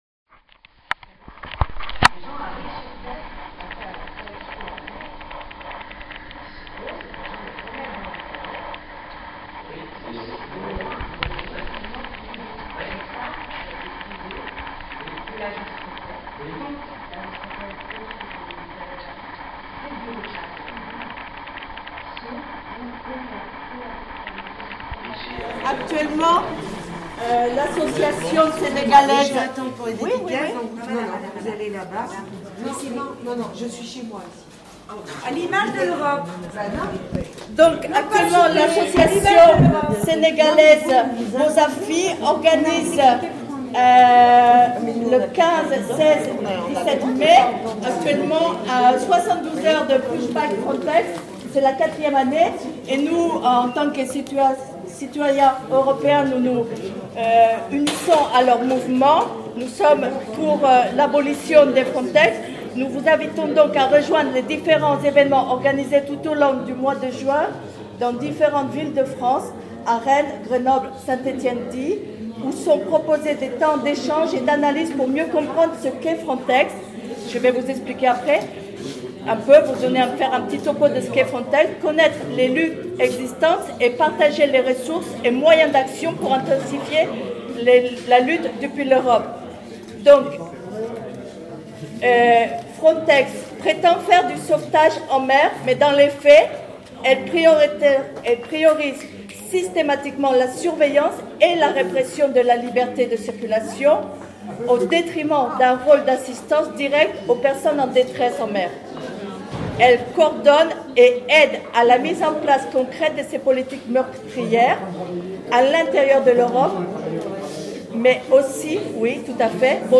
La maison de l’ Europe invitait à venir questionner le fonctionnement des institutions européennes. Nous avons donc questionné, mais on ne nous a pas laissé faire bien longtemps ;) On nous coupe, on nous prend le micro, on arrache une prise de parole...
maison_de_l_europe.mp3